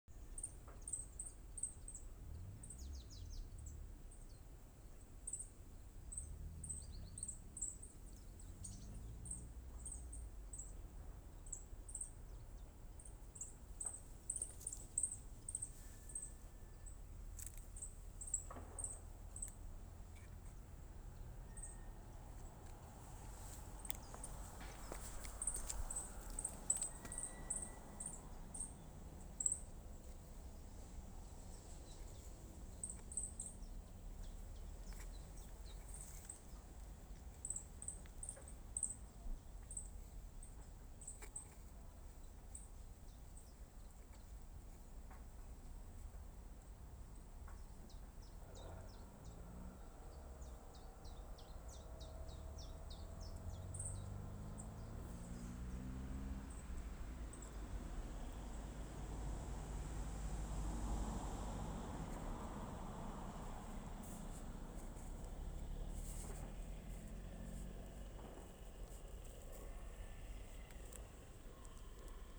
Bird Aves sp., Aves sp.
Administratīvā teritorijaCēsu novads
StatusVoice, calls heard